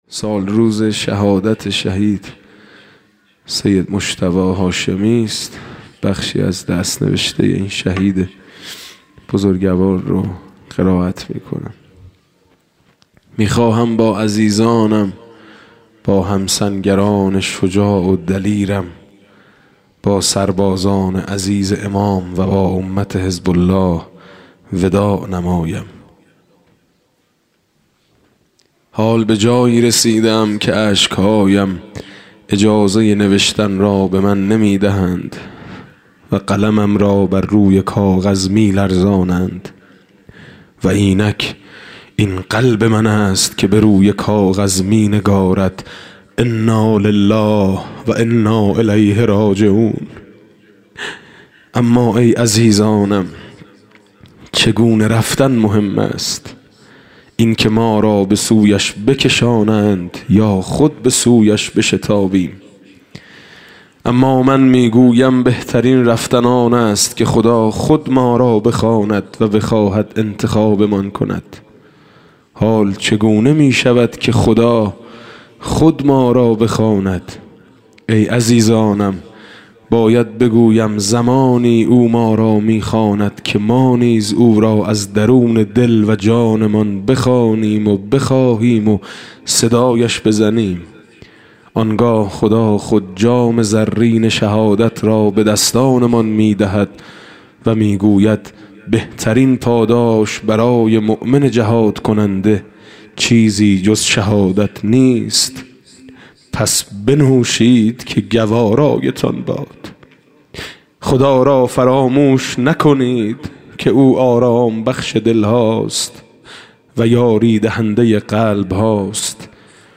دانلود مناجات شب سوم ماه رمضان الکریم ۱۳۹۷با نوای حاج میثم مطیعی